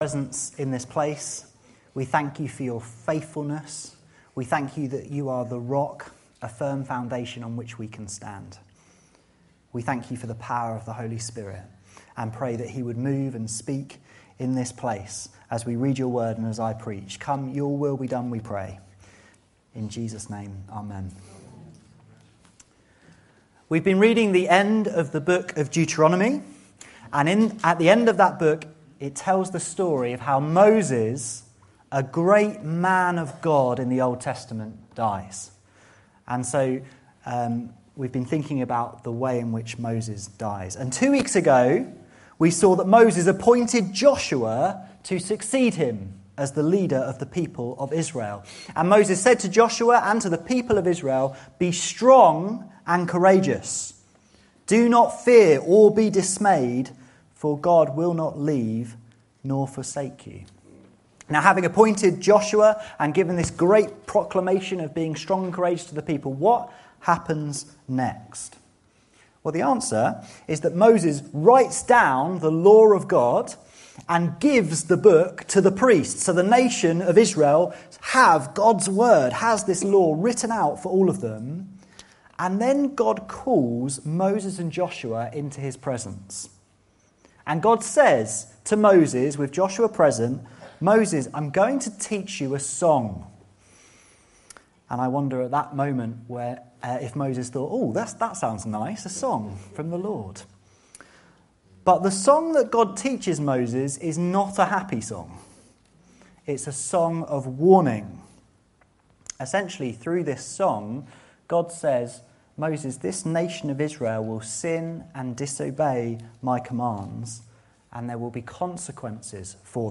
This sermon reflects on God’s song of warning spoken through Moses to all of humanity.